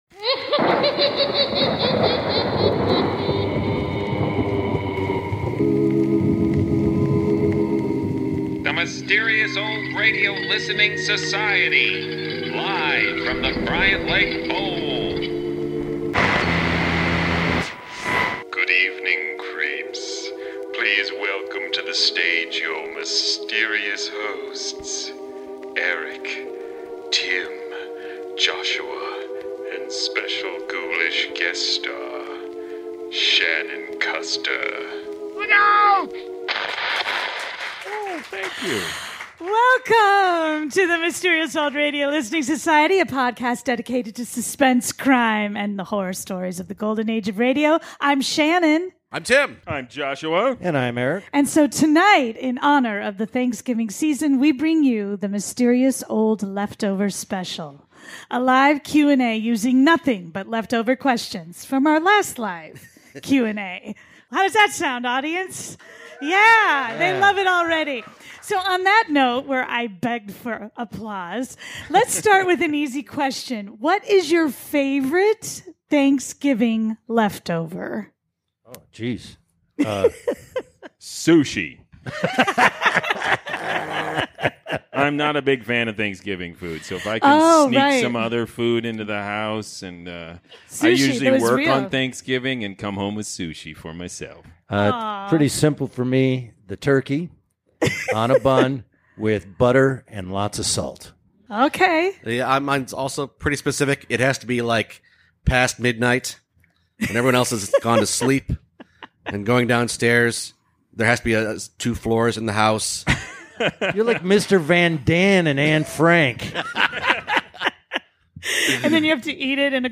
Thanksgiving has passed but it’s too soon for Christmas, and that means it’s time for leftovers!